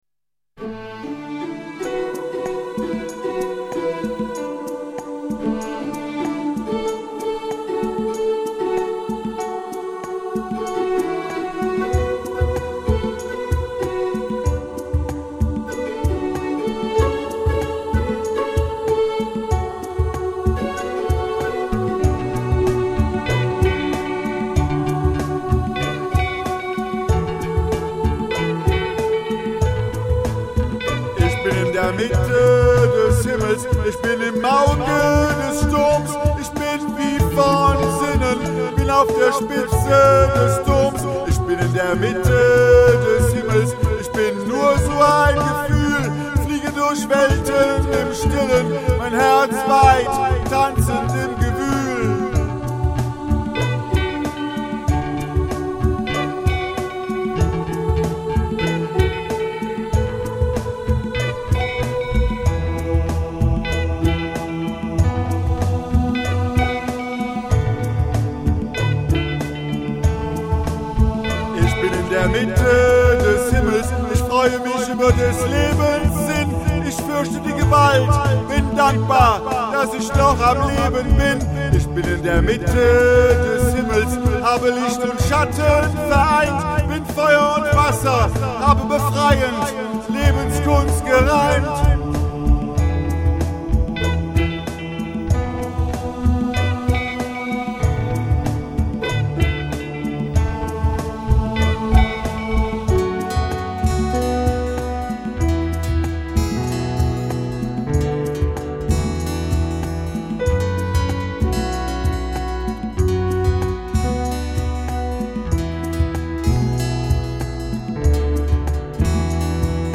" now in a vocal version.